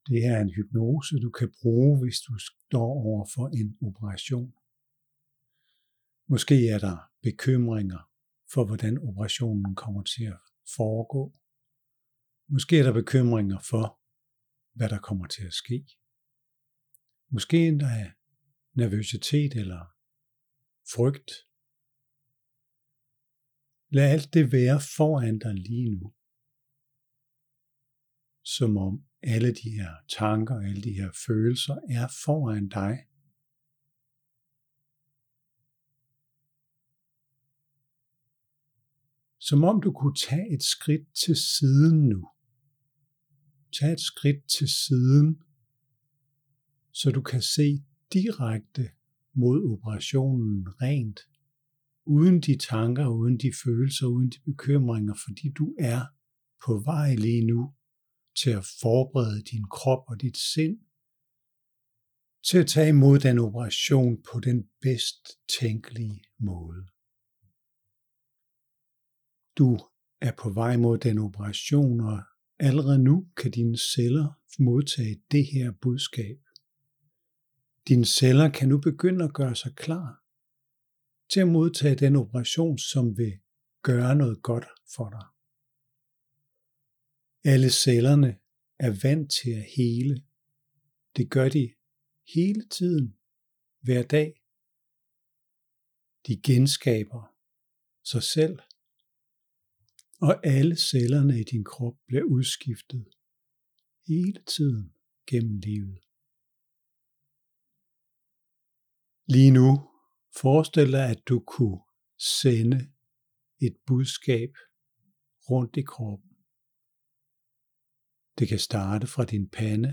Her er en lille demonstration af en hypnose, der kan forberede kroppen til at tage imod en operation.